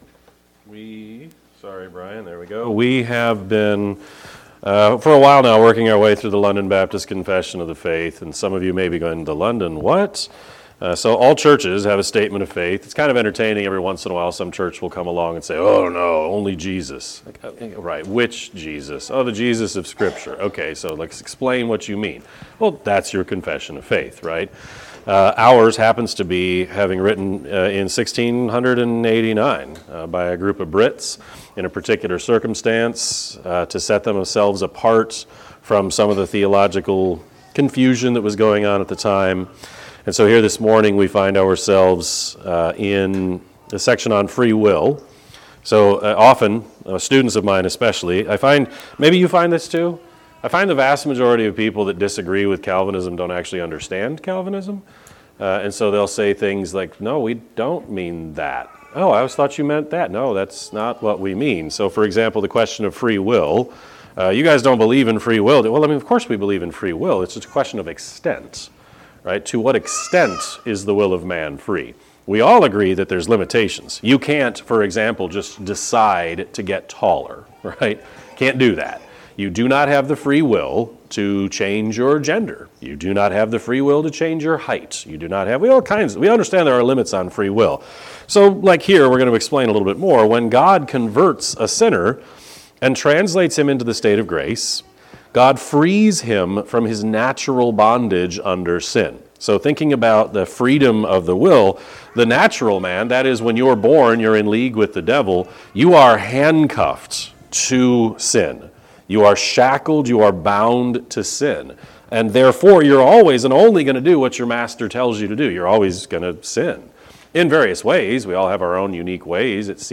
Sermon-3-1-26-Edit.mp3